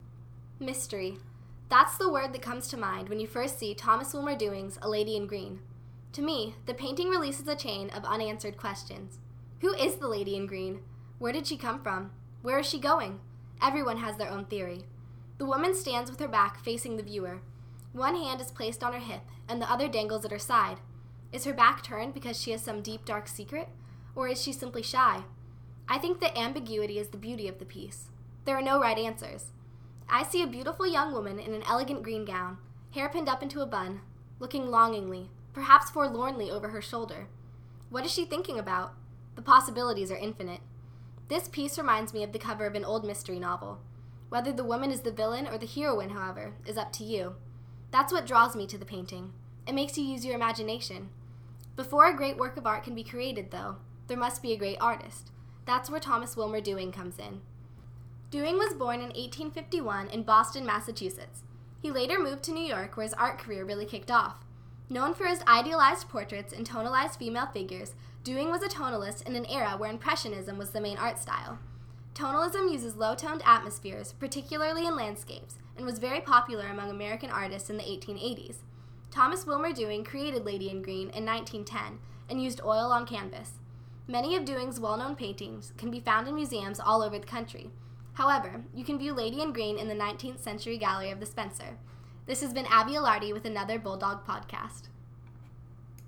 Audio Tour – Bulldog Podcast